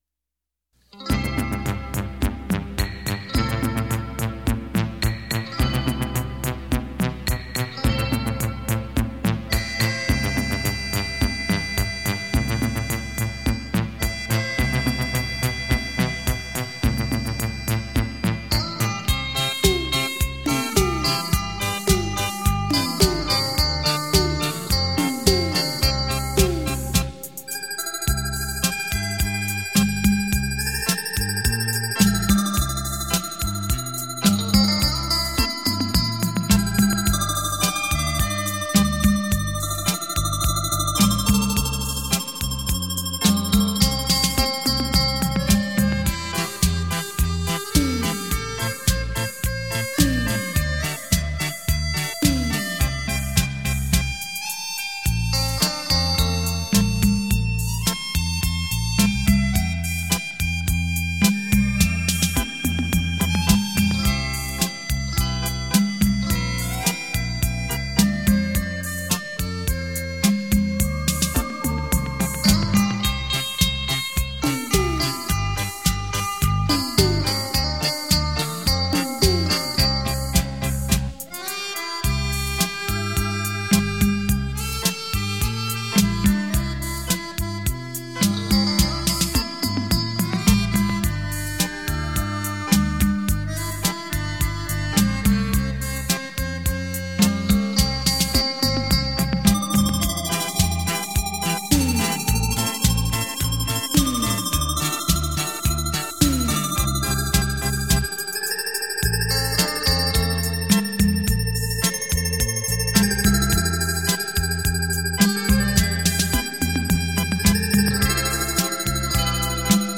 电子琴演奏